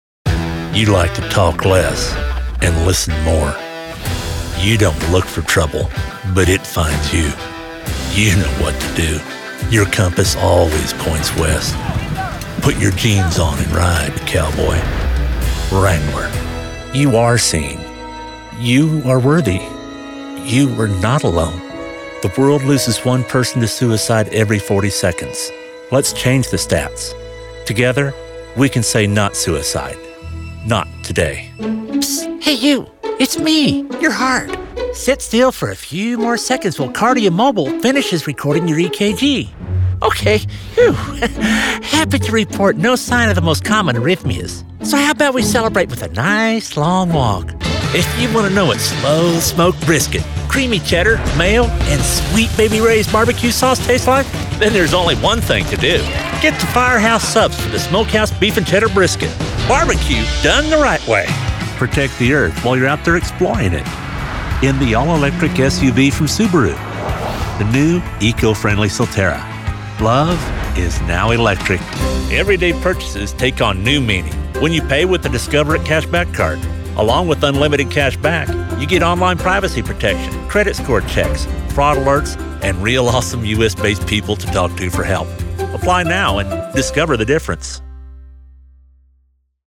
Male
English (North American)
Adult (30-50), Older Sound (50+)
Radio Commercials
Commercial Demo